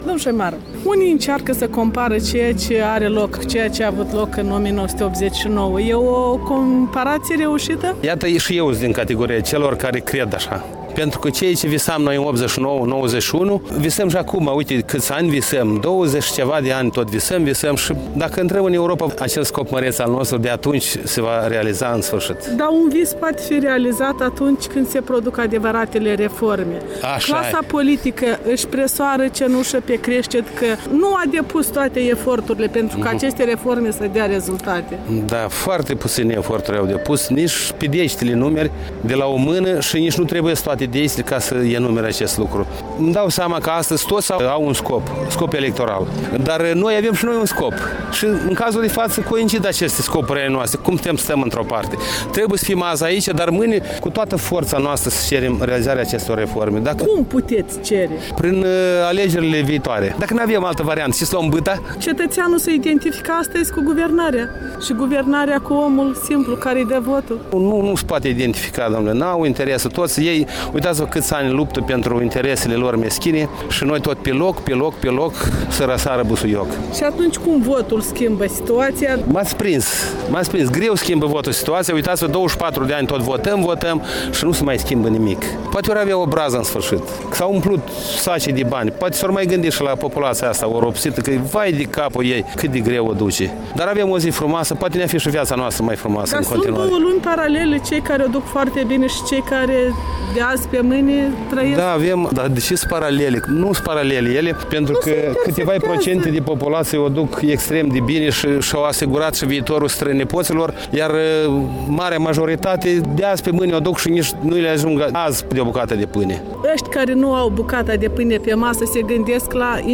În dialog cu Vasile Șoimarul la marea demonstrație în sprijinul integrării europene